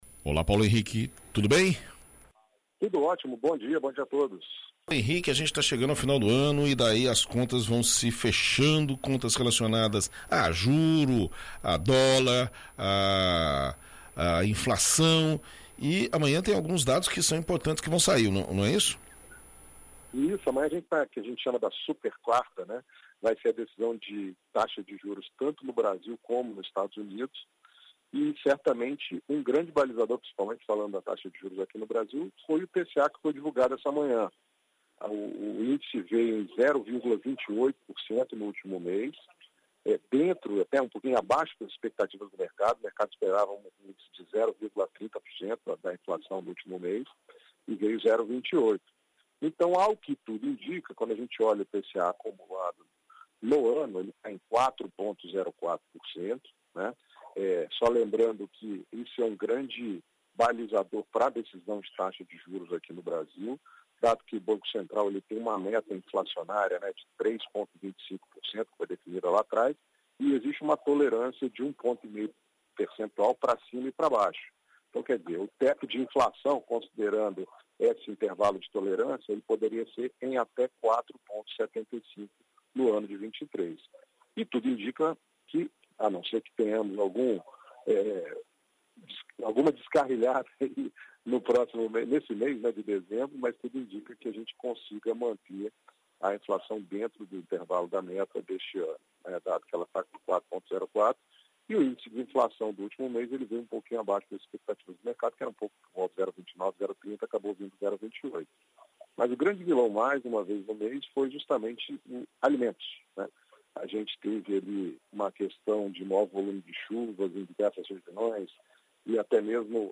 Na coluna “Seu Dinheiro” desta semana na BandNews FM Espírito Santo